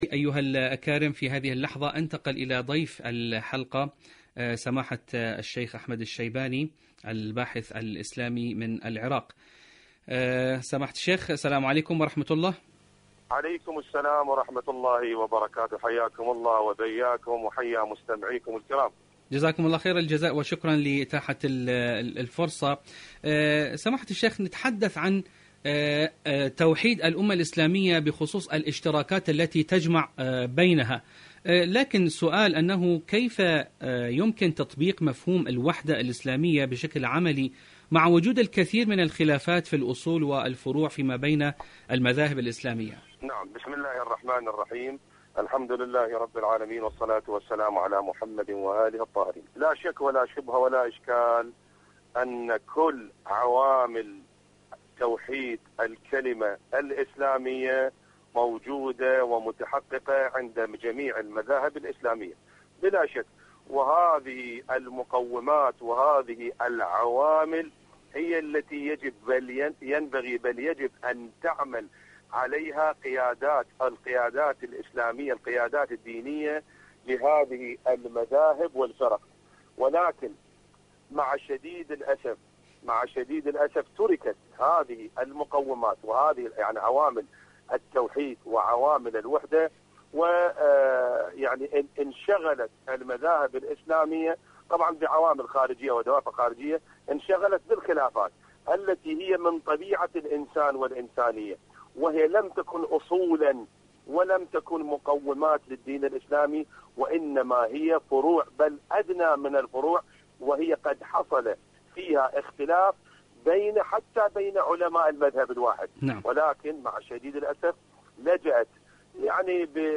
إذاعة طهران-دنيا الشباب: مقابلة إذاعية